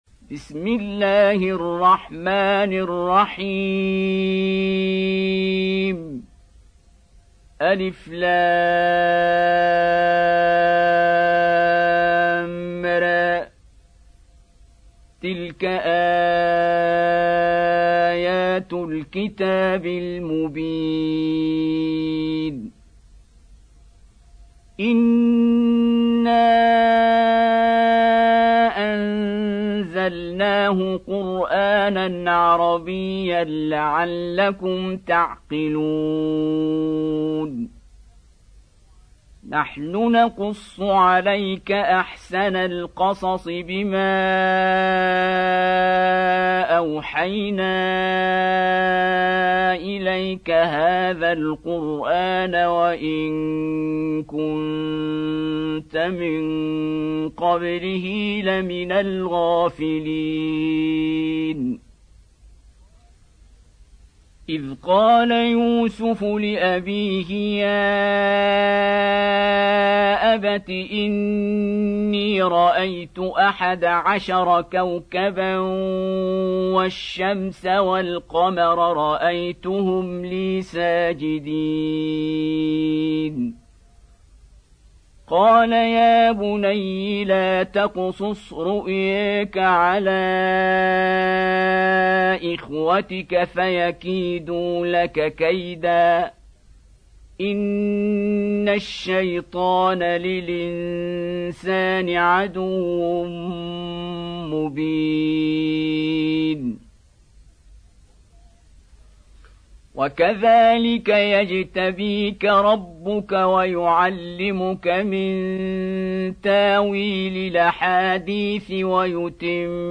Surah Yusuf Beautiful Recitation MP3 Download By Qari Abdul Basit in best audio quality.